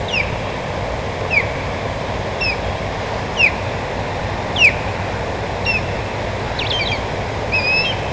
Rybołów - Pandion haliaetus
głosy